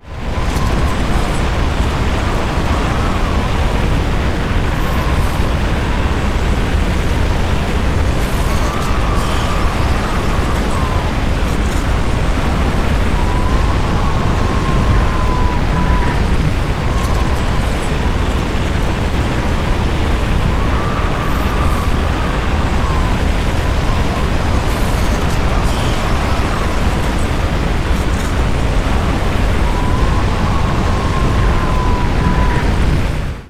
sfx updates